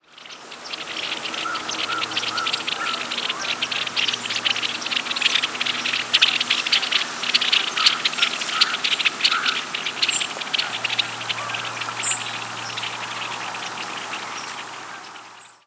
Red-winged Blackbird diurnal flight calls
Flock in flight primarily giving "pwik" calls. American Crow, Canada Goose, and White-throated Sparrow in the background.